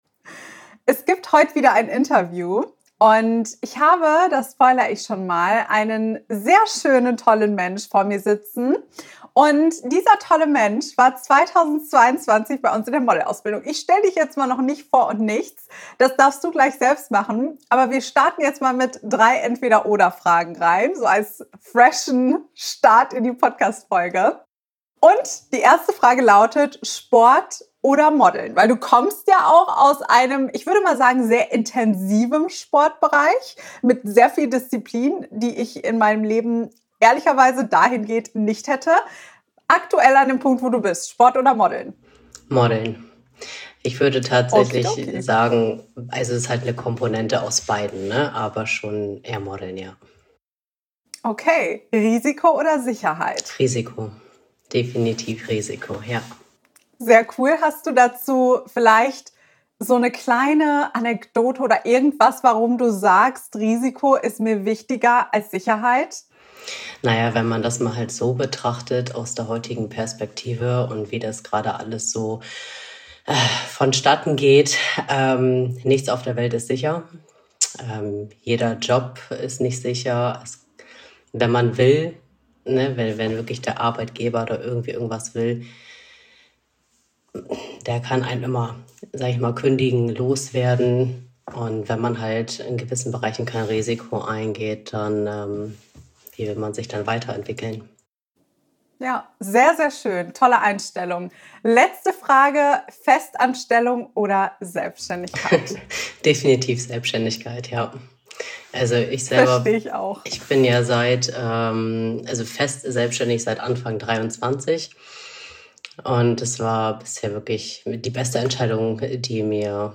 #227: Geduld und Zeit sind fundamental für eine erfolgreiche Modelkarriere - Interview